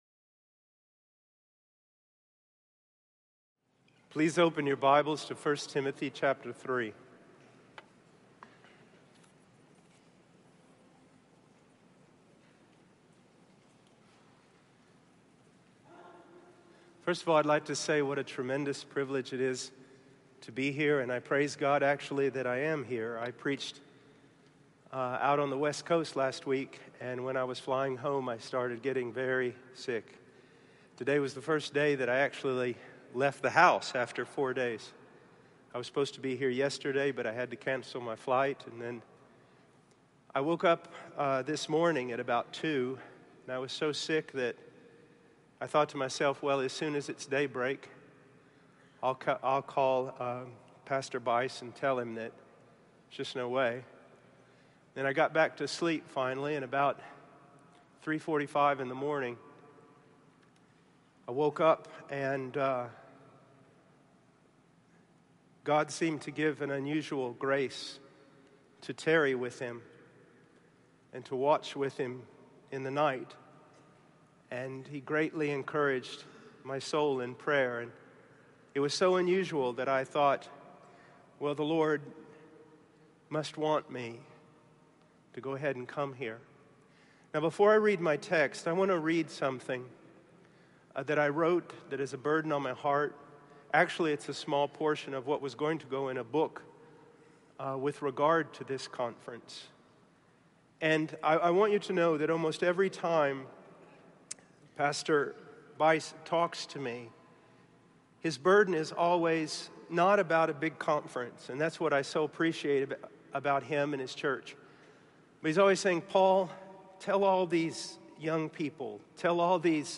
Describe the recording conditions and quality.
This message was given at the G3 Conference, and is video embeded from their YouTube channel here.